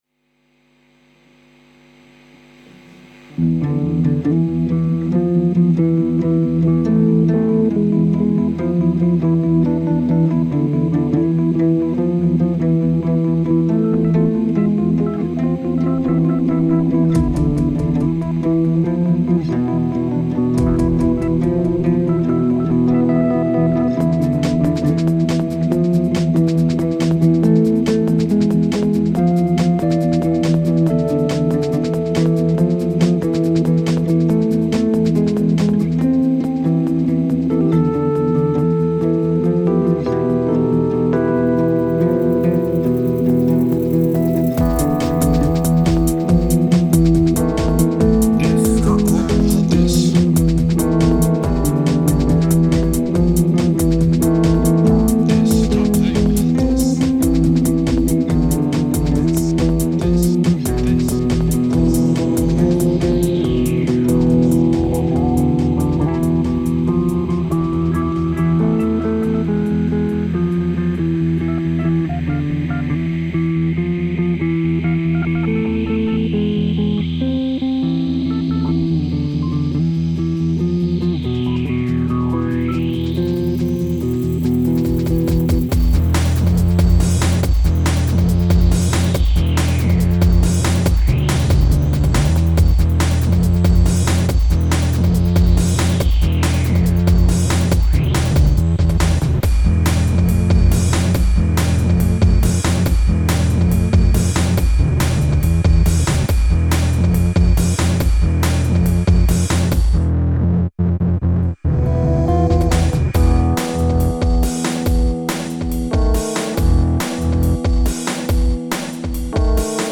Vos Compos Electronique
(desolé pour le mix du premier je suis pas ingé-son)
bon c'est vrais que le son est bof la gutare manque de clarté les drums son beaucoup trop en retrait domage car ca devrais beaucoup plus peter
gros manque de patate general